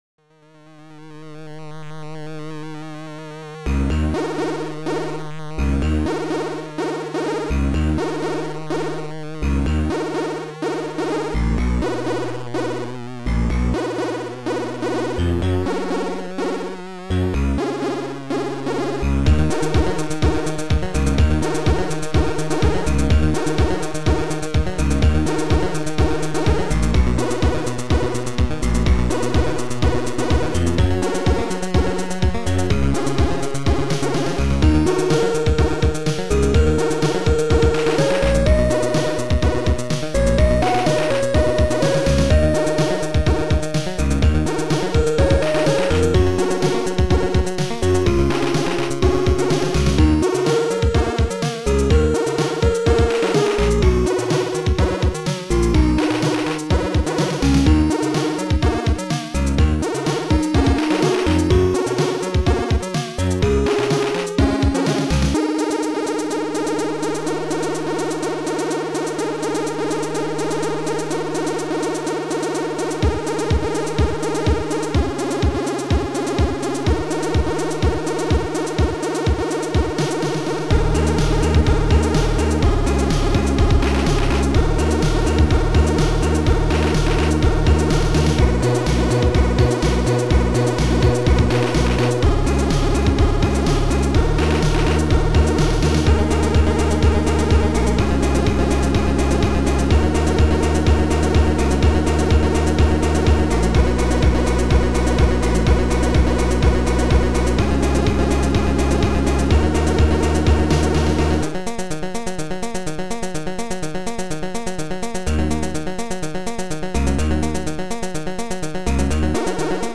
Future Composer Module